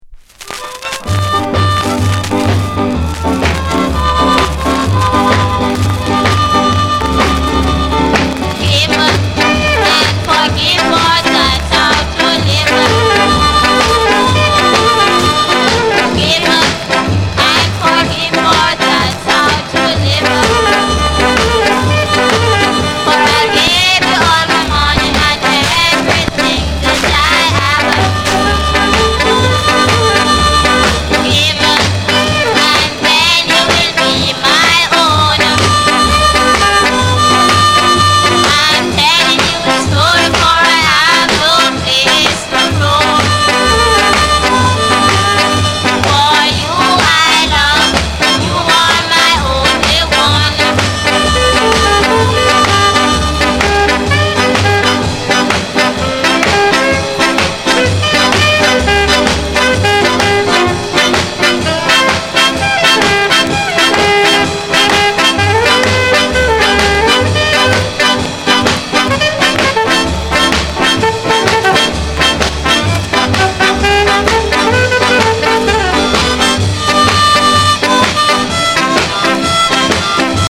Genre: Ska